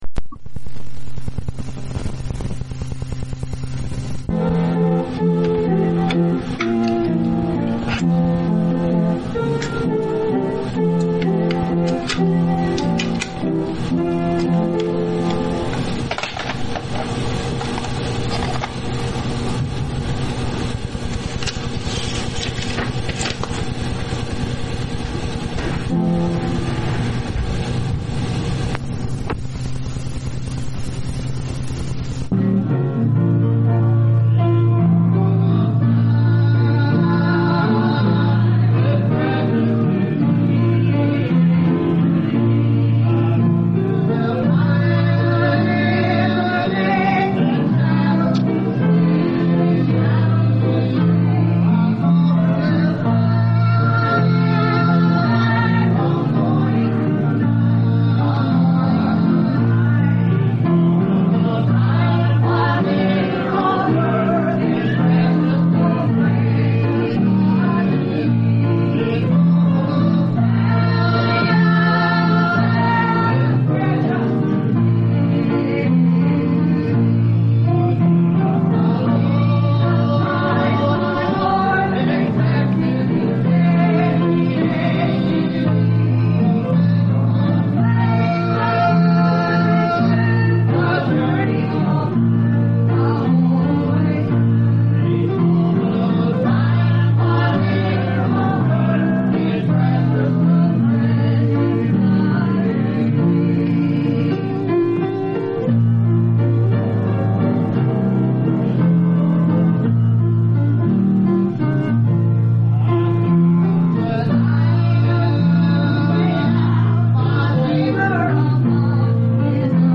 Ordination MVBC 1986